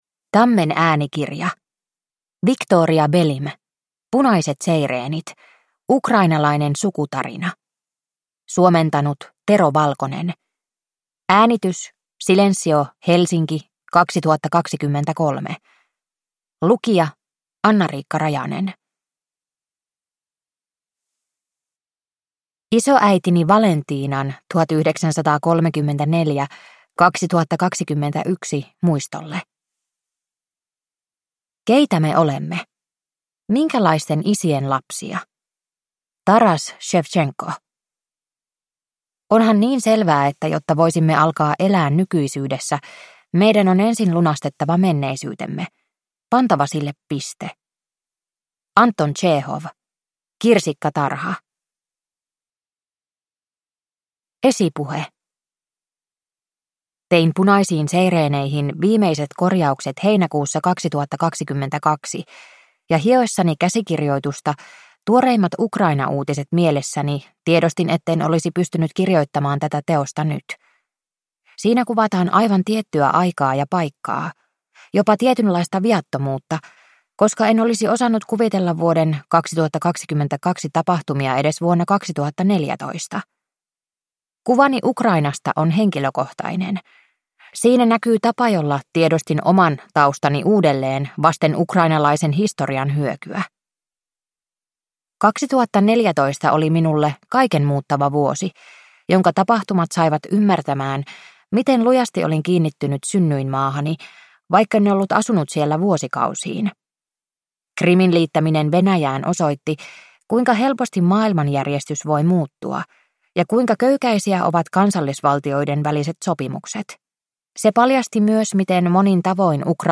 Punaiset seireenit – Ljudbok